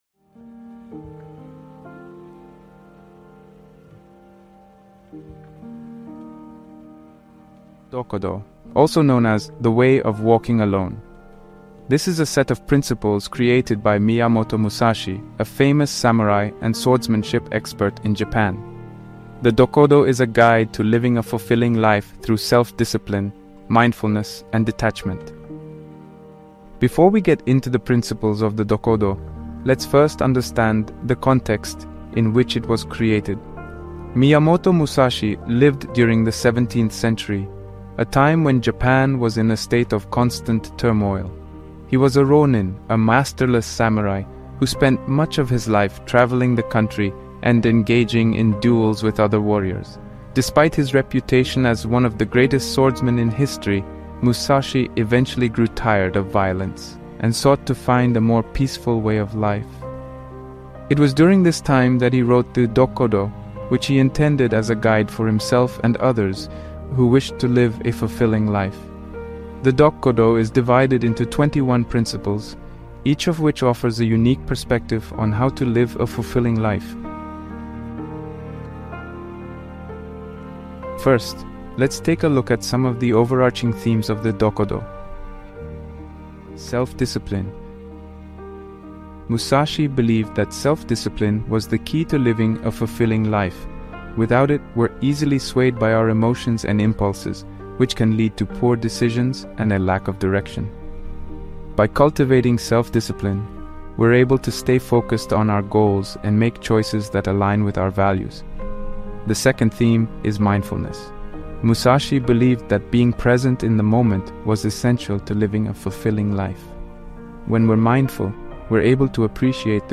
Deep Calm Soundscape for Focus, Sleep, and Stress Release